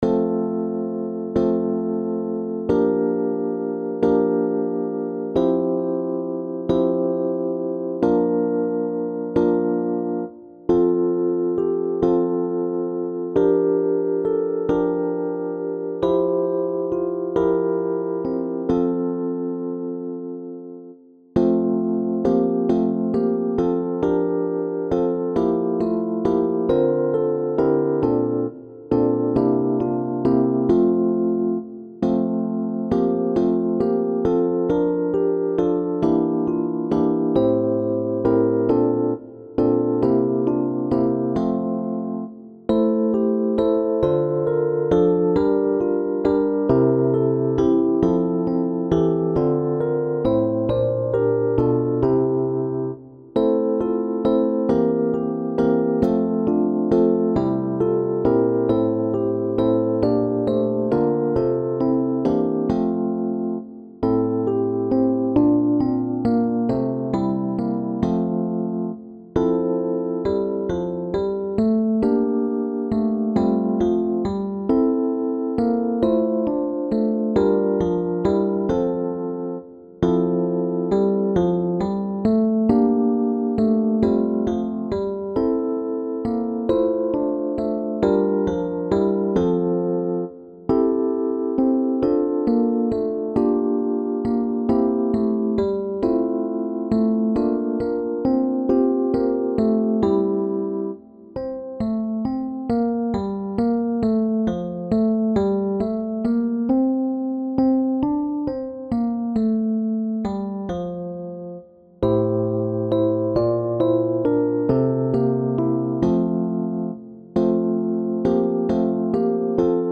SATB (div.)